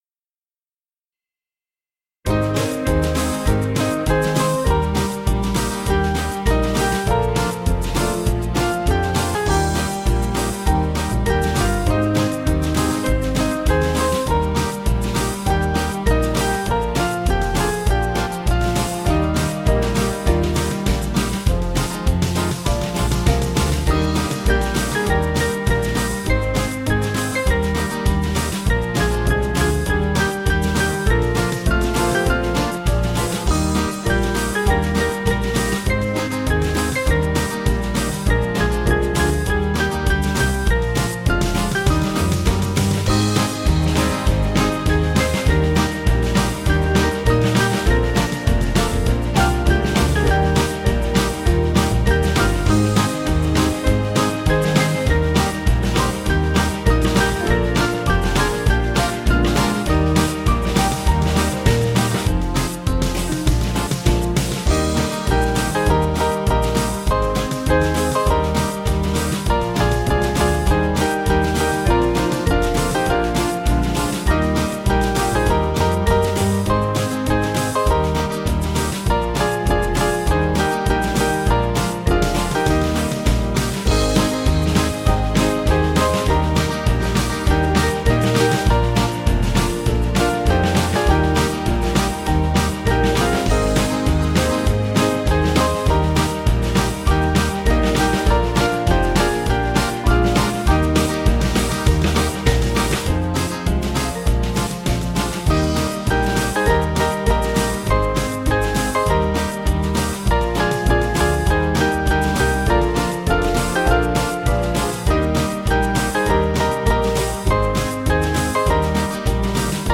Small Band
(CM)   3/Eb 484.3kb